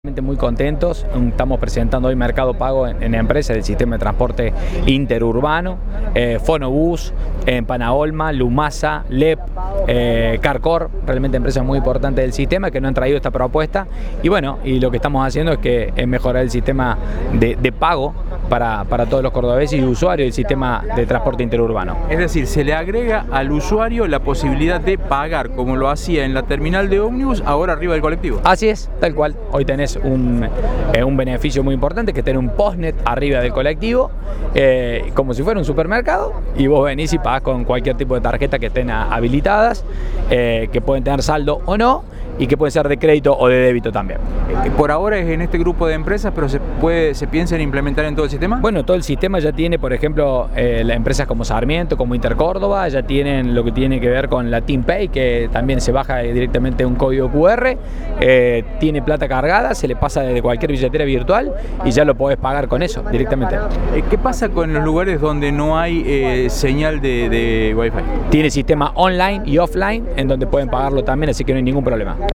Audio: Marcelo Rodio (Sec. de Transporte de Córdoba).